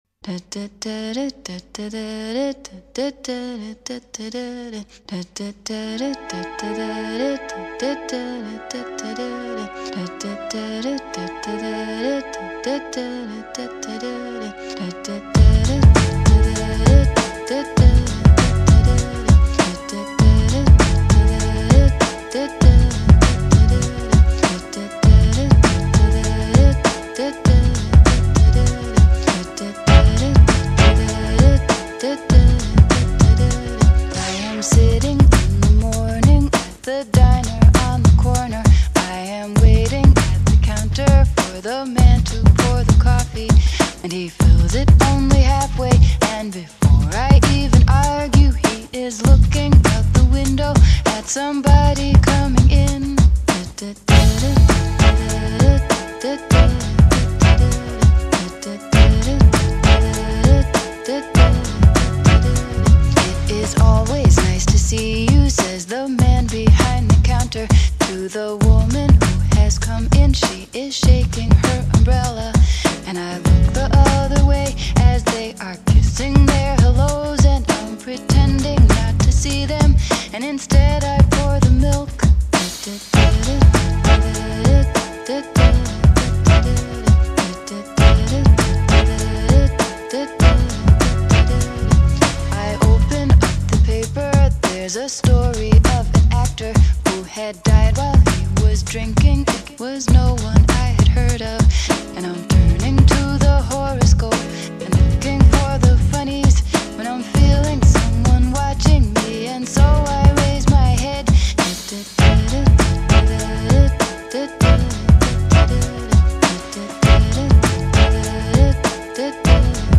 没有附加任何乐器伴奏
听这首歌怎么也不觉得是新民谣